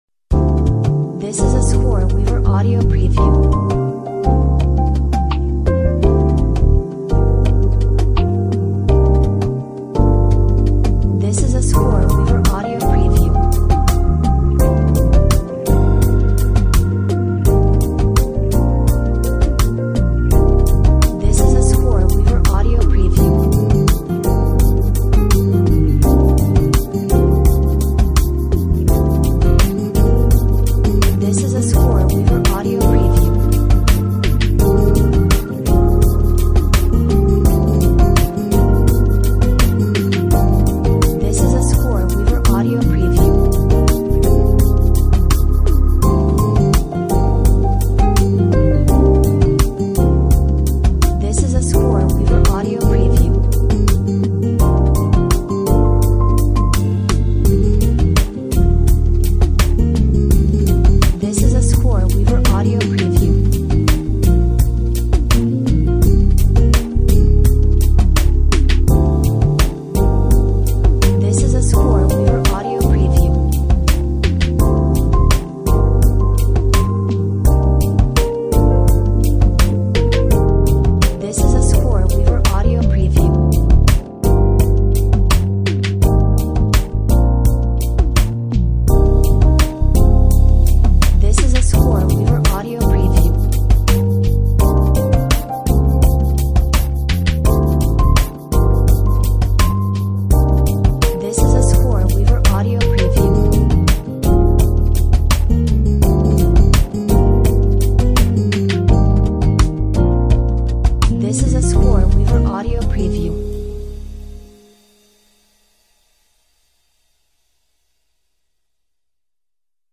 Jazzy Soul/RnB Song
Smooth Soul/RnB Song